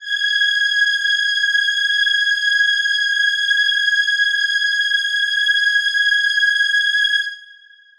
Choir Piano
G#6.wav